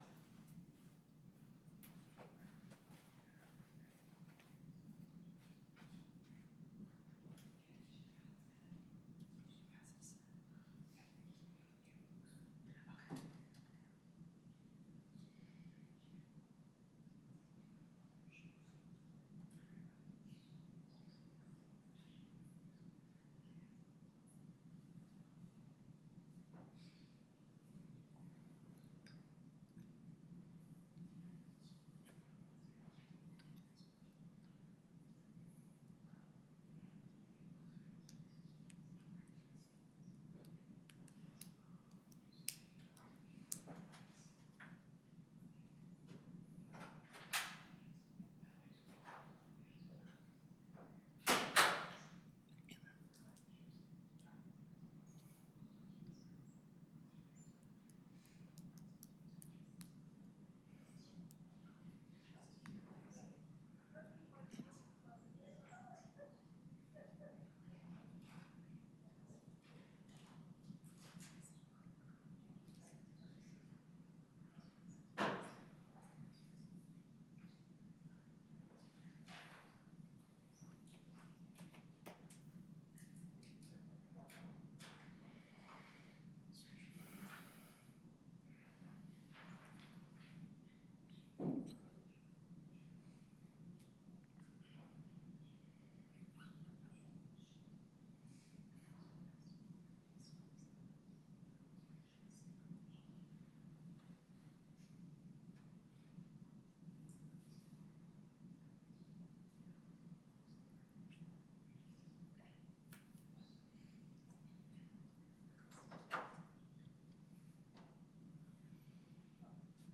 Before the En Banc Court, Chief Justice Herndon presiding Appearances